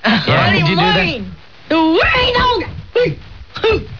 We'll ask Zach doing again the Oklahoma accent, 'cause he does it very well, the accent of the peasants from there.
Could you do just a little bit of the Oklahoma accent, from the farmers ?
farmers.au